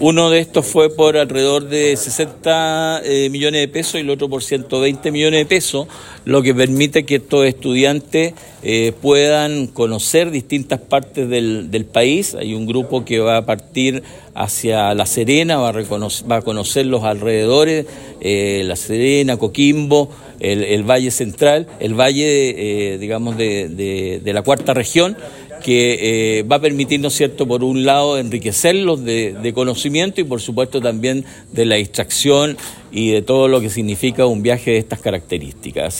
Jorge Castilla, presidente del Concejo Municipal, destacó la relevancia de esta adjudicación, valorando el hecho de que se estén destinando recursos para apoyar a los jóvenes en sus actividades formativas.